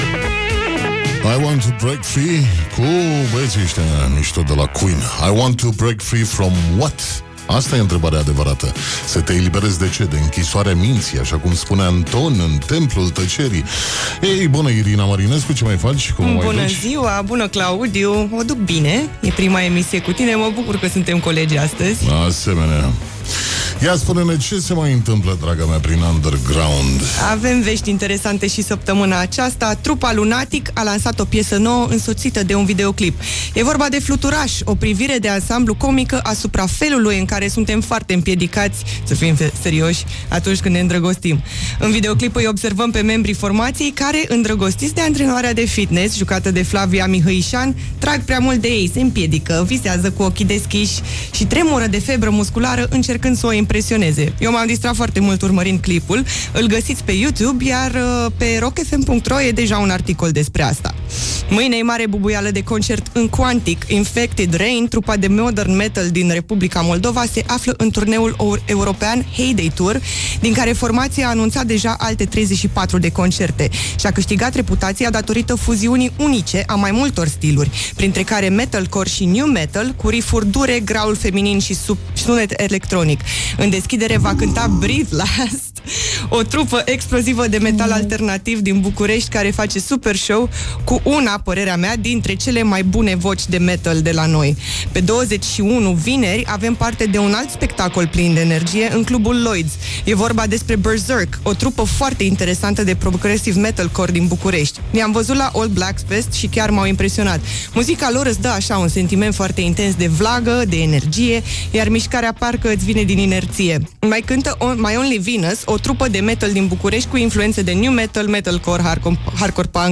Am revenit în studio